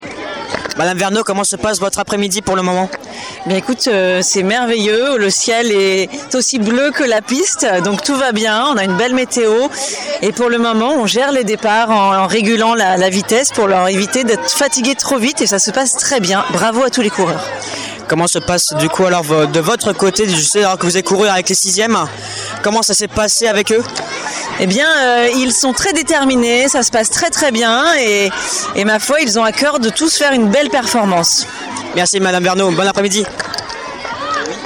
Interview
Le cross du collège 2025 !